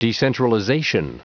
Prononciation du mot decentralization en anglais (fichier audio)
Prononciation du mot : decentralization